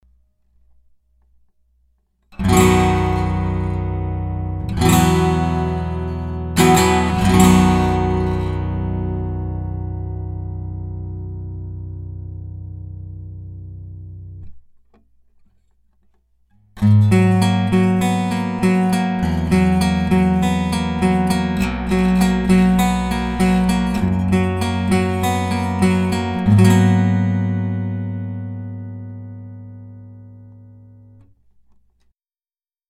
ピエゾピックアップシステムの完成です。
とはいえ、音色の自然さはインブリッジタイプの非ではないと思いますし、 一々マイクを立てずとも、シールド刺せば音が録れると言うのはやはり便利だと思います。